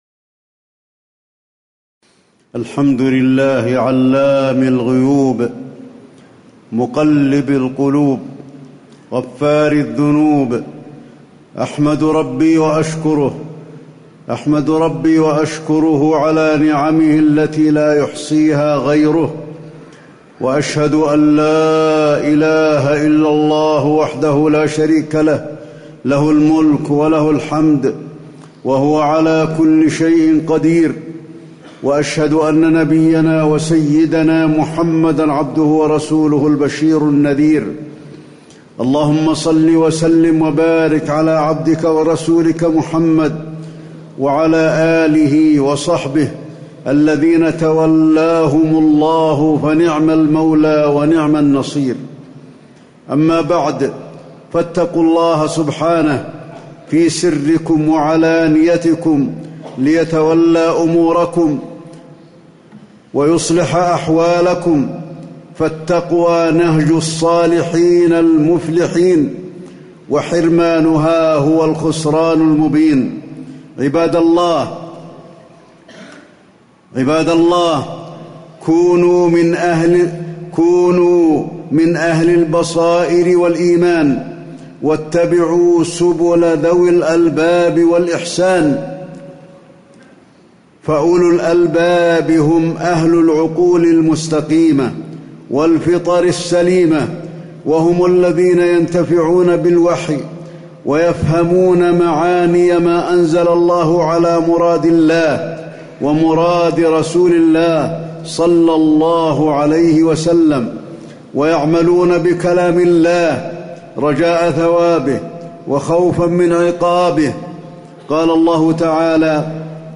تاريخ النشر ١٧ رجب ١٤٣٨ هـ المكان: المسجد النبوي الشيخ: فضيلة الشيخ د. علي بن عبدالرحمن الحذيفي فضيلة الشيخ د. علي بن عبدالرحمن الحذيفي الاعتبار بأحداث التاريخ